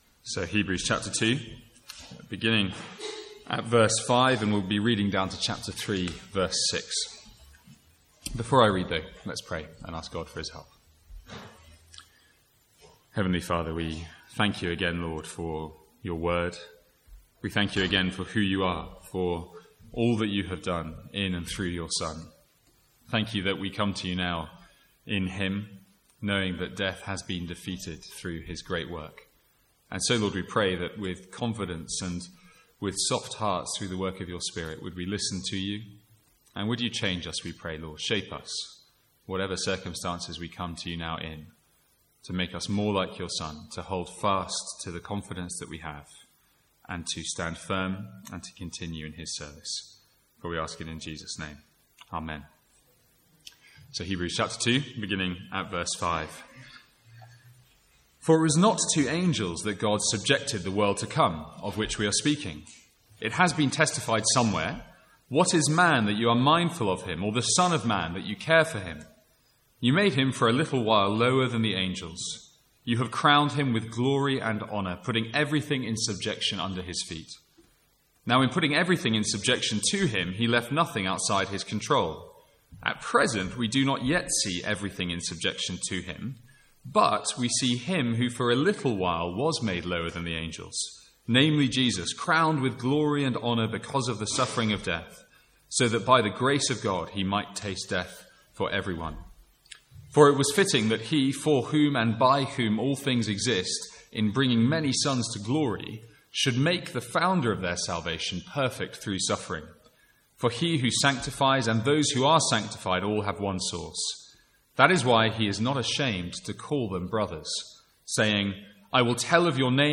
From the Sunday evening series in Hebrews.